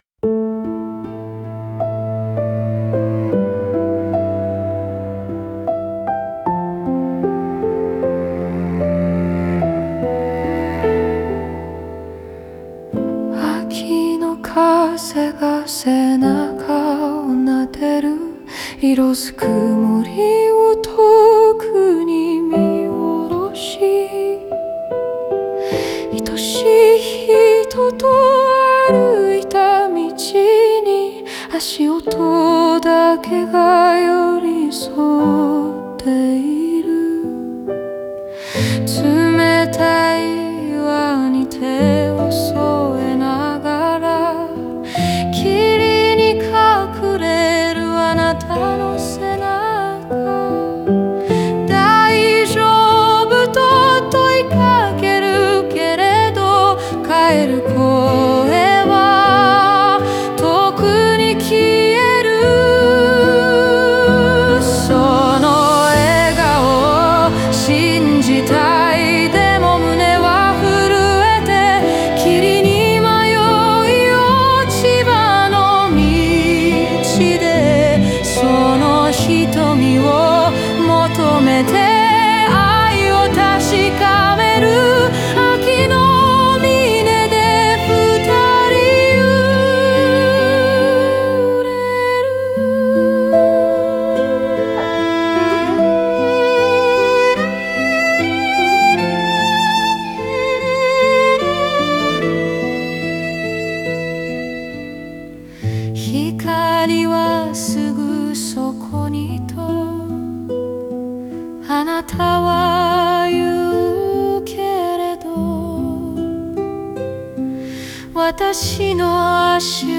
オリジナル曲♪
女性目線の語り歌として構成され、柔らかく深みのある声で語りかけるように想いを伝える表現が特徴です。